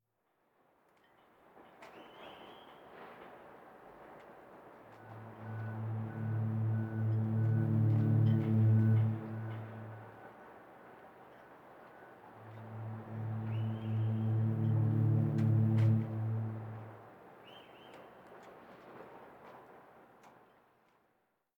Sirena de un barco
sirena
Sonidos: Transportes